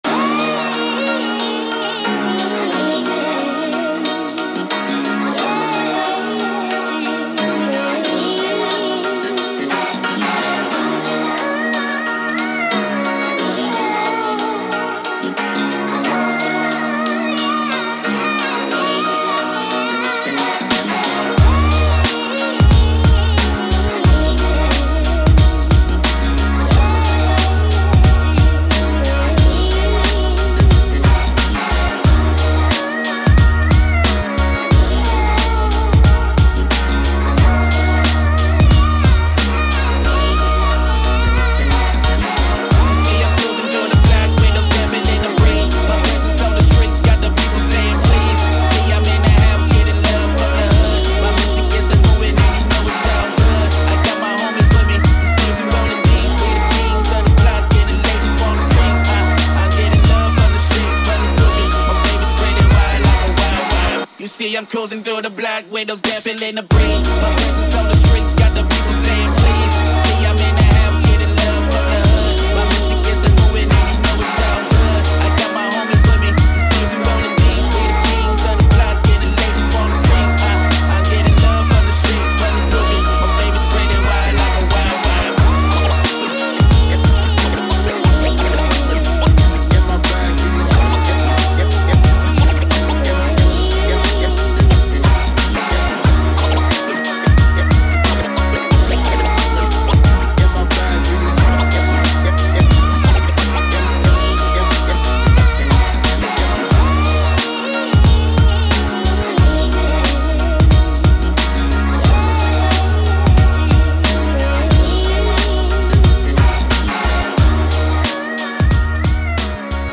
Текст песни Музыка ДИНАМИЧНАЯ МУЗЫКА